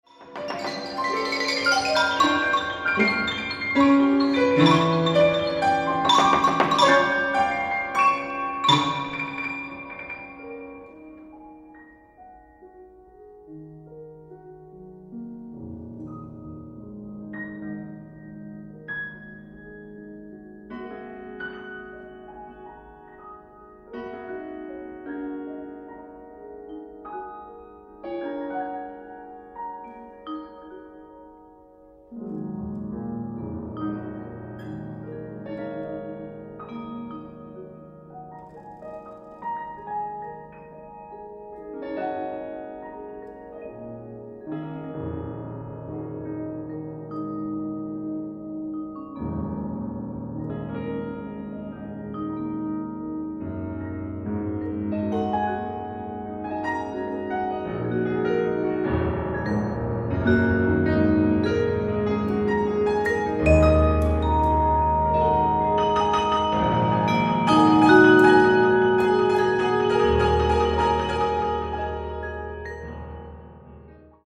Australian, Classical